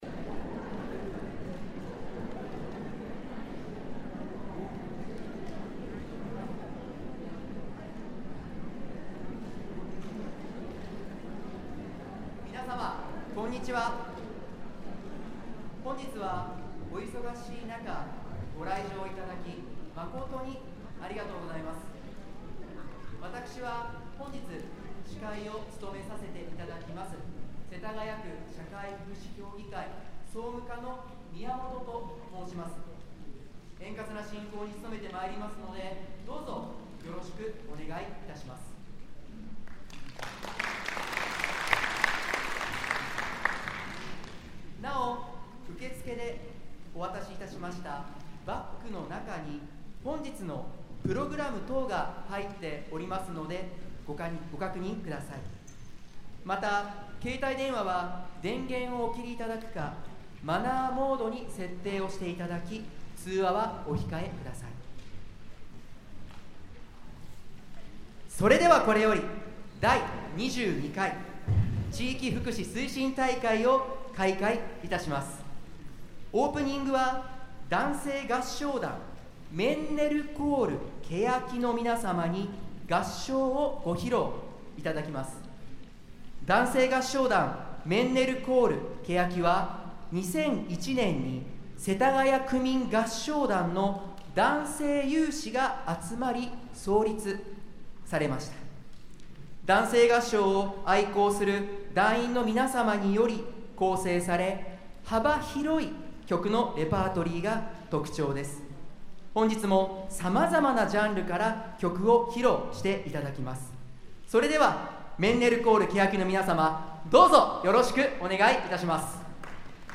世田谷区第22回地域福祉推進大会オープンアクト2024年11月24日 (日) 演奏